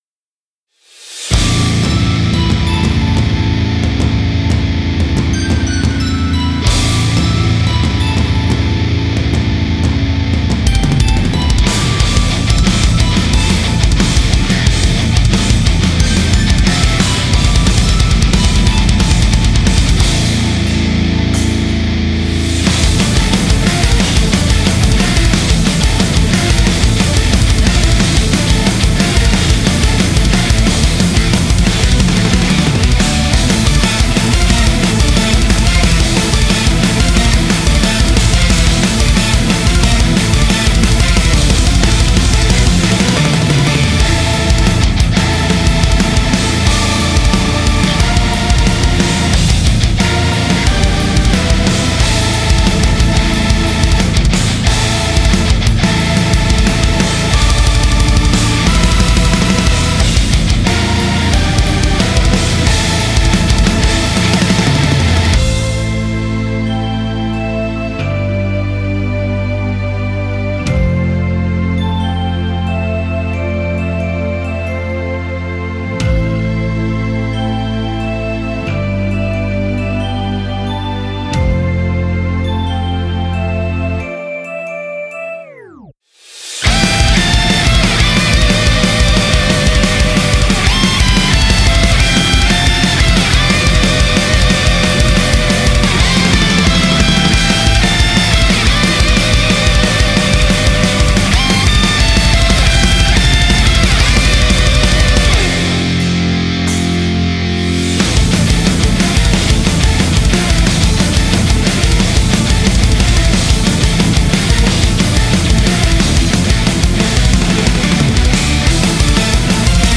Hard Arrange